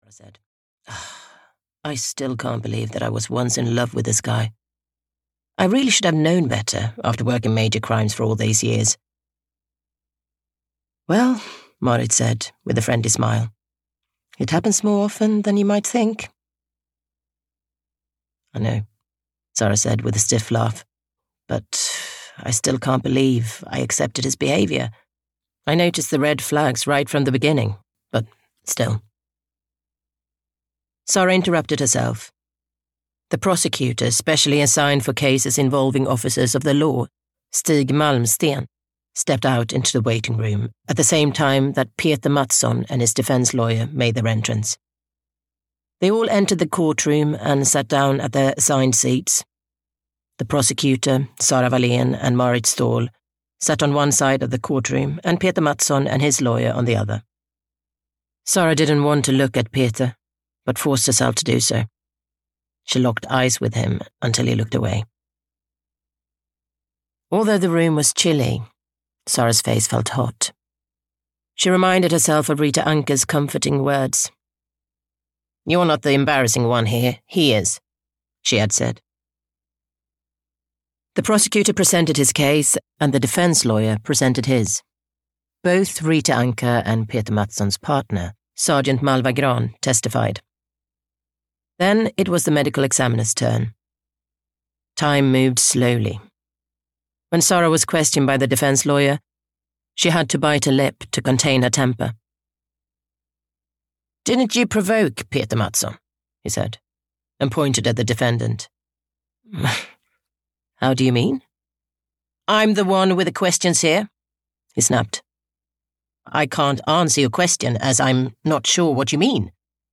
Vigilante (EN) audiokniha
Ukázka z knihy